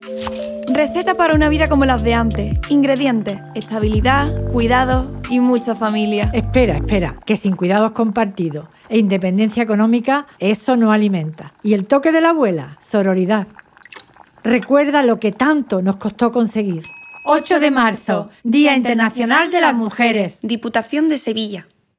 Cuña de radio Campaña 8 de marzo de 2026